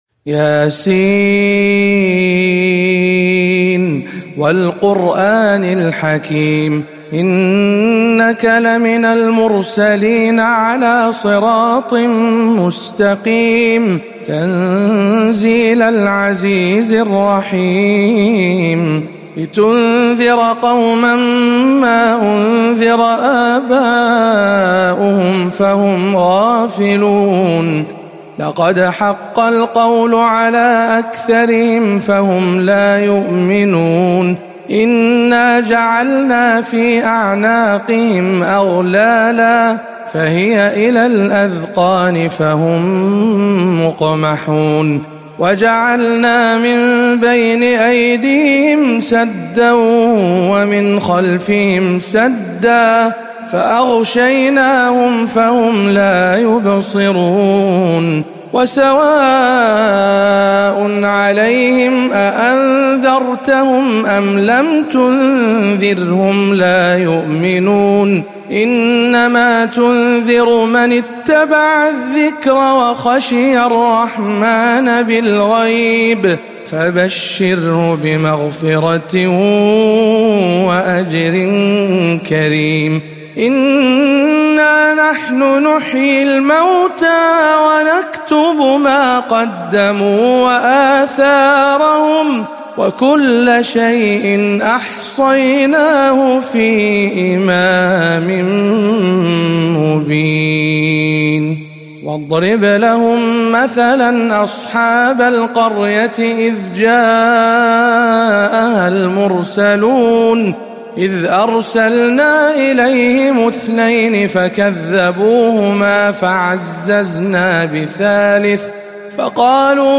سورة يس بجامع حسان بن ثابت بجدة